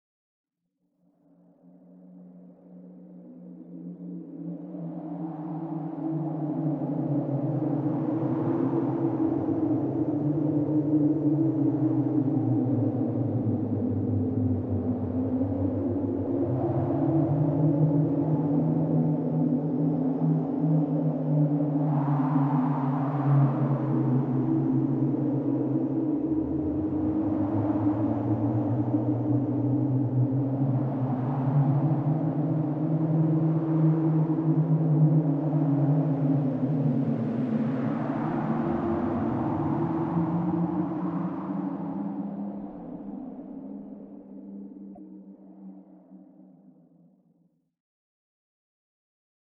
feat:ambiance
squelettes.mp3